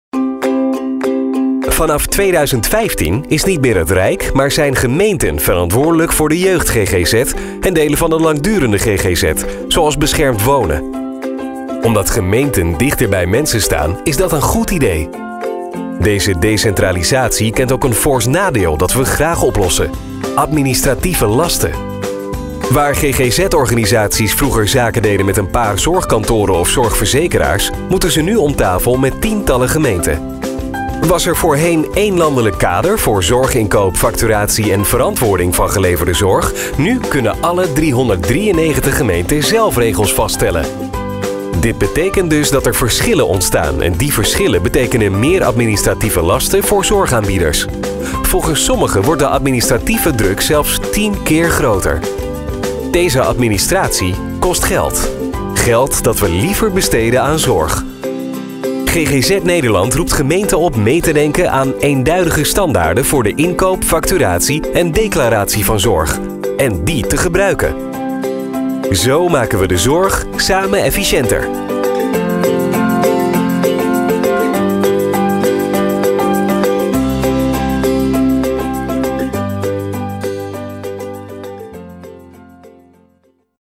Dutch, Nieuwe Nederlandse voiceover, Commercial voiceover, new dutch voice talent
Sprechprobe: Industrie (Muttersprache):